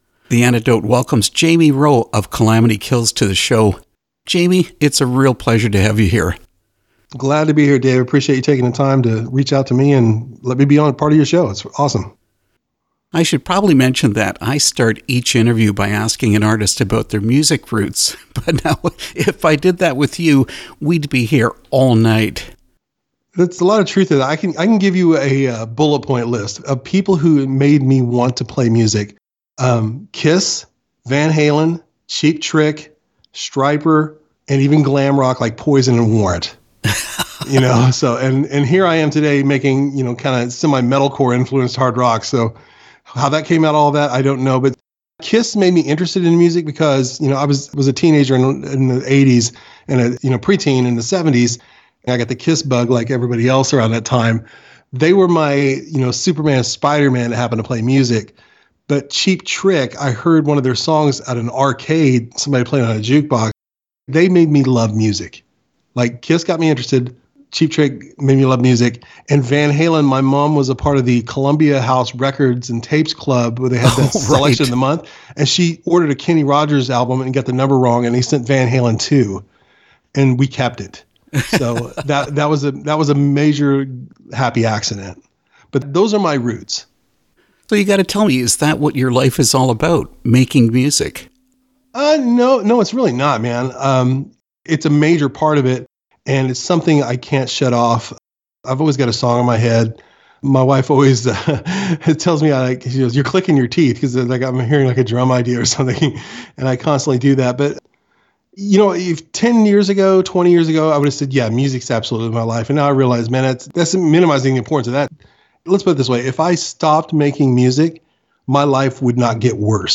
Interview with KALAMITY KILLS
kalamity-kills-interview.mp3